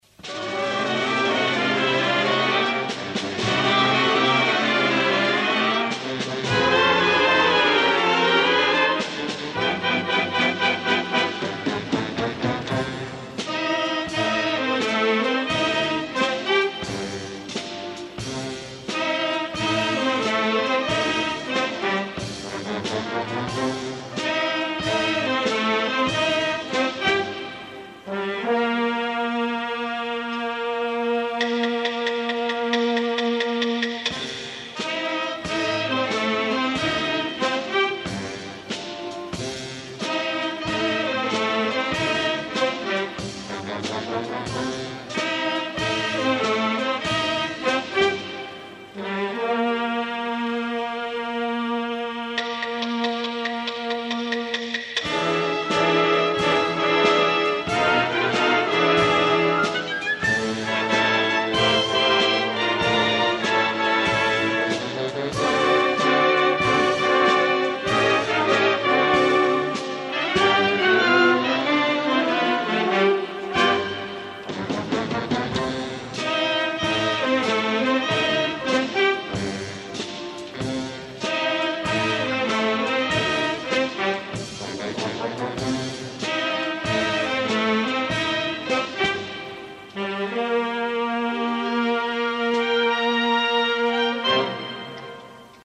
Concert extraordinari 1988. Esglesia parroquial de Porreres Nostra Senyora de la Consolació.